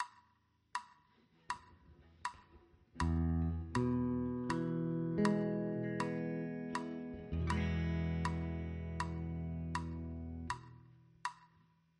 Ex 1 – E-Dur